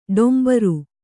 ♪ ḍombaru